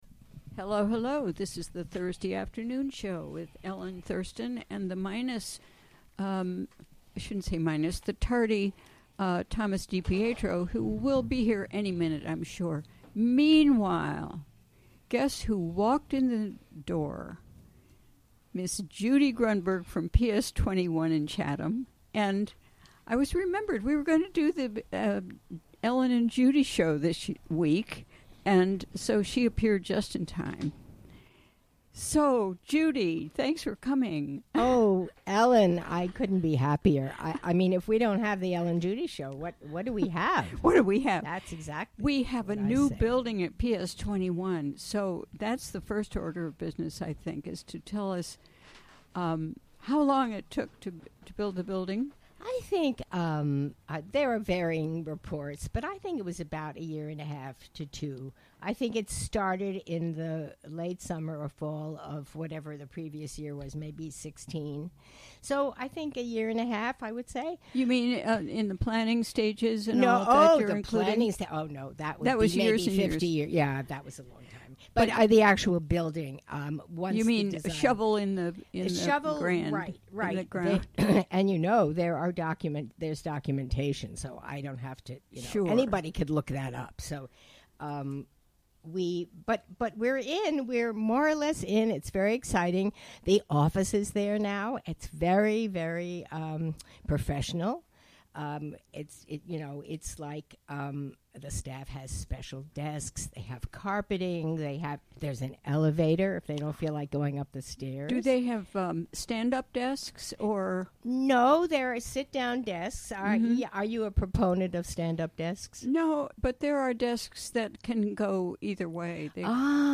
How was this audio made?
Recorded live during the WGXC Afternoon Show on Thursday, March 1, 2018.